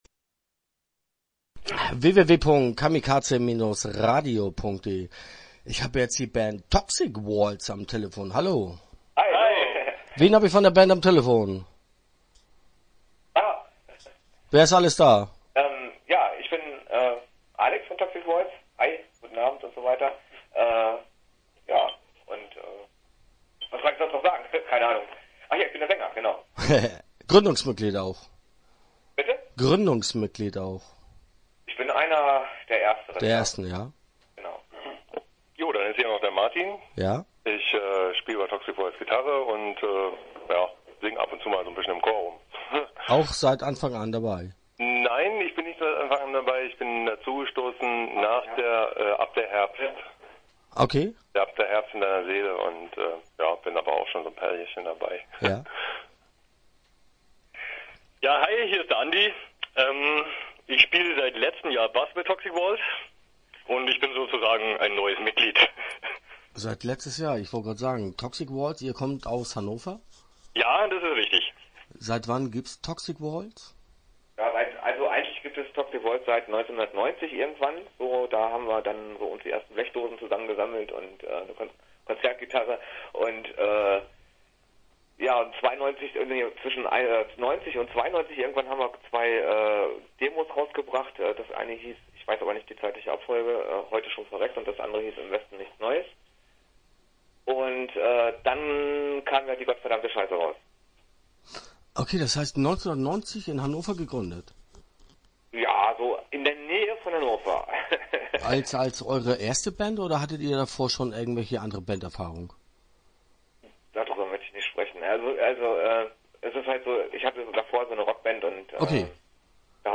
Start » Interviews » Toxic Walls